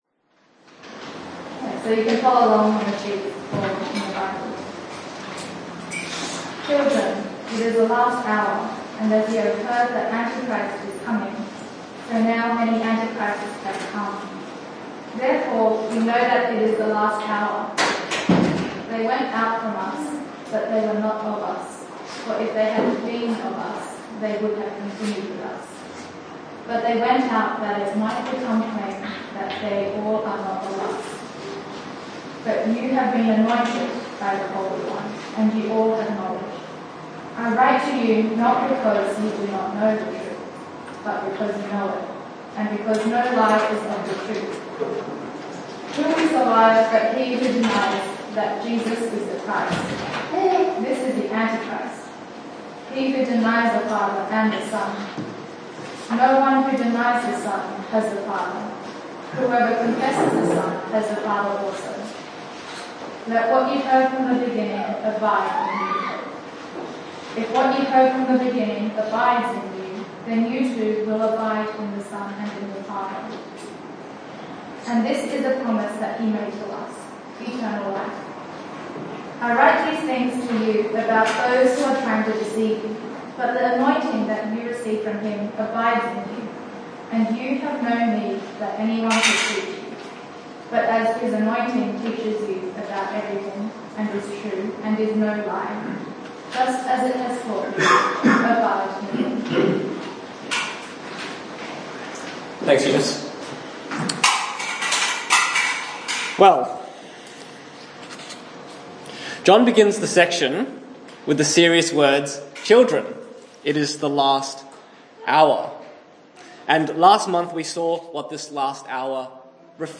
This is Part II of a multiple part message looking at 1 John 2:18-27.